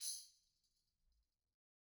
Tamb1-Hit_v1_rr1_Sum.wav